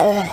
ough.ogg